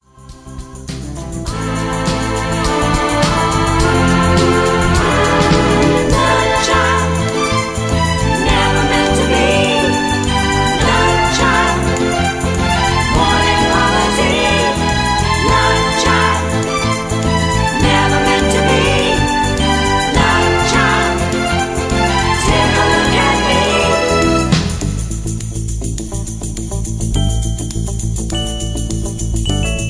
(Key-A) Karaoke MP3 Backing Tracks
Just Plain & Simply "GREAT MUSIC" (No Lyrics).